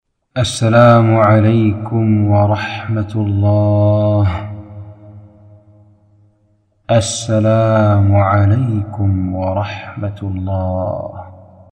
✨✨How to pronounce Assalaamu ‘alaykum wa rahmatullah 👇👇